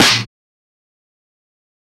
Clap